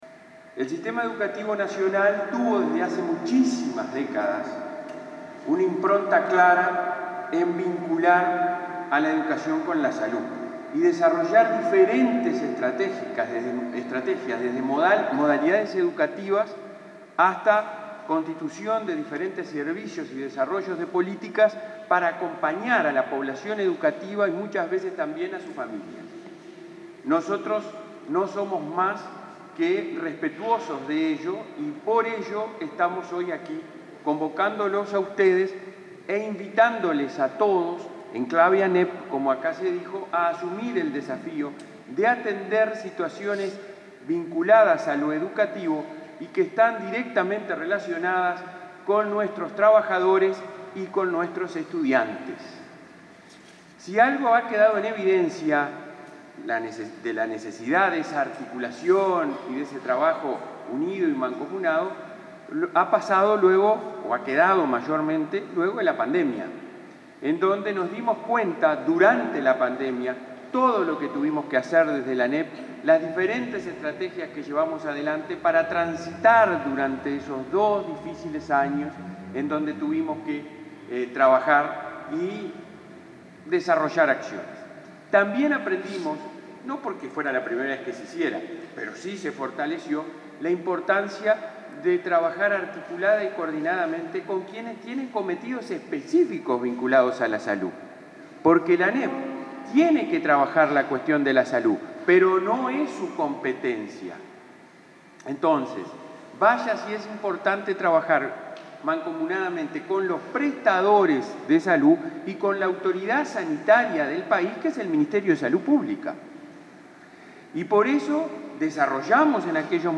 Palabras del presidente de ANEP, Robert Silva
Palabras del presidente de ANEP, Robert Silva 28/04/2023 Compartir Facebook X Copiar enlace WhatsApp LinkedIn El presidente de la Administración Nacional de Educación Pública (ANEP), Robert Silva, participó en la presentación de la nueva Dirección Sectorial de Salud de ese organismo, en el marco del Día Mundial de la Seguridad y la Salud en el Trabajo.